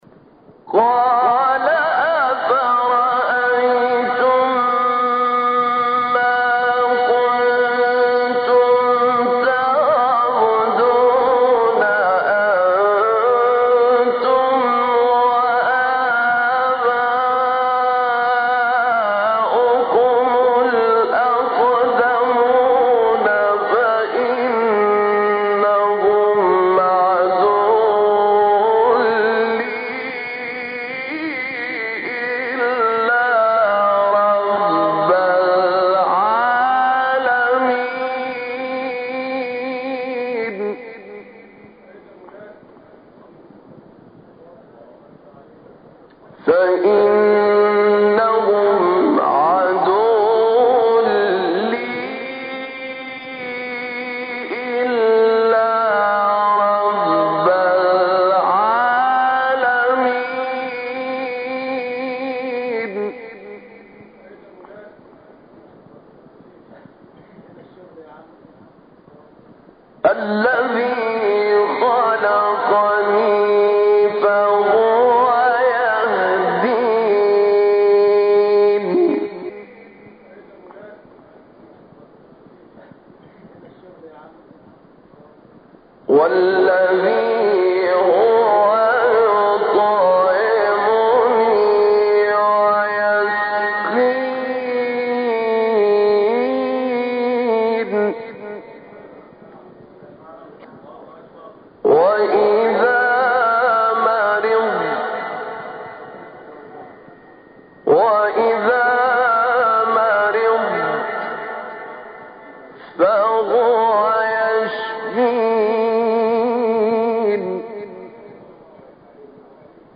تلاوت مقام صبا استاد طاروطی | نغمات قرآن | دانلود تلاوت قرآن